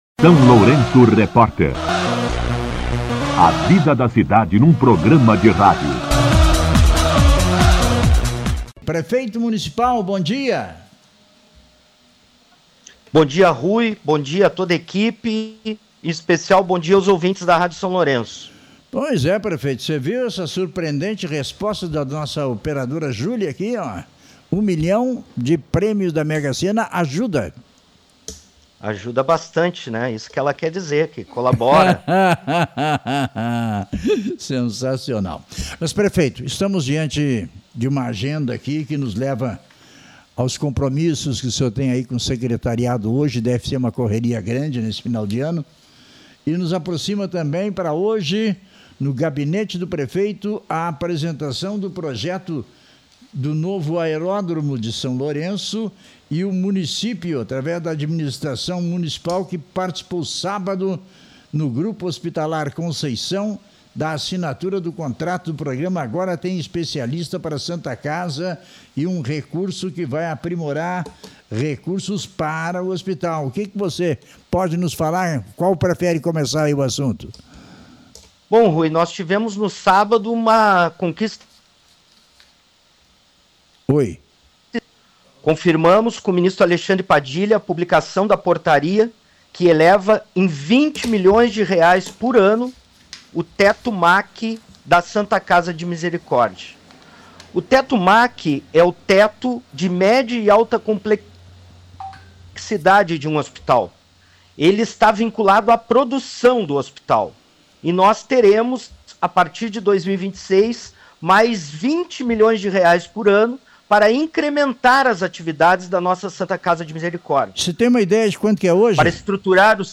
Entrevista com o O prefeito Zelmute Marten
O prefeito Zelmute Marten concedeu entrevista ao SLR RÁDIO nesta segunda-feira (22) para falar sobre a elevação de R$ 20 milhões por ano no Teto MAC (Média e Alta Complexidade) destinado à Santa Casa de Misericórdia de São Lourenço do Sul.